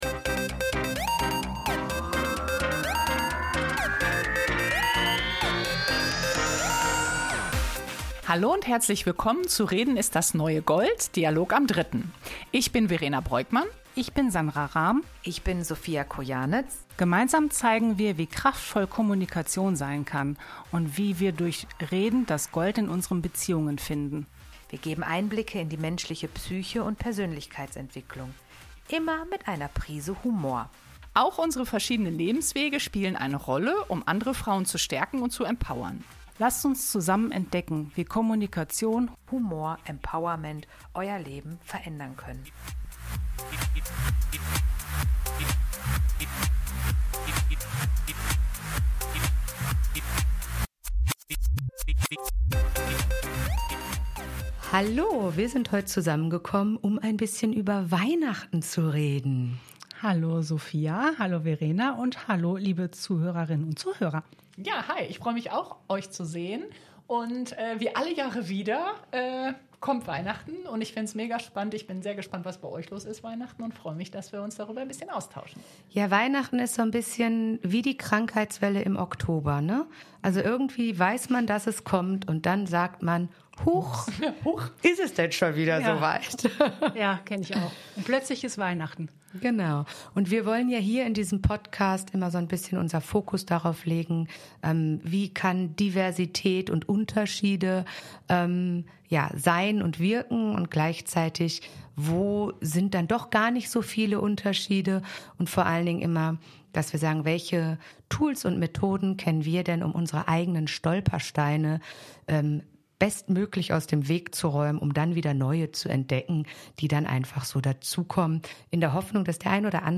Beschreibung vor 4 Monaten Weihnachts-Spezial: Drei Frauen, drei Leben, drei Perspektiven Diese Folge ist ein echtes Spezial: unvorbereitet, ungefiltert und überraschend persönlich.
Trotz der Spontanität entsteht ein warmes, ehrliches Gespräch, aus dem sich ganz nebenbei kleine praktische Impulse mitnehmen lassen: Ideen, wie man mit Weihnachtsstress umgehen kann, wie man Erwartungen anpasst oder wie man für sich selbst gute Momente schafft. Eine besondere Folge, die zeigt, wie unterschiedlich Weihnachten, die Weihnachtszeit und deren Herausforderungen sein können.